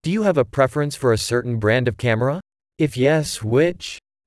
Manipulaties waren erg subtiel, zoals te zien en horen in dit voorbeeld:
B. Spraak gegenereerd van tekst, met prosodie die past bij de informatie status van de gebruiker
In afbeelding B (met handmatige manipulatie) is het accent op ‘preference’ verwijderd. Bovendien is er een accent toegevoegd op de lettergreep ‘brand’, omdat het zingedeelte ‘a certain brand of camera’ nieuwe informatie is binnen de vraag; het is nieuw voor de luisteraar dat de vraag over cameramerken gaat.
geluidsbestand-met-manipulatie.wav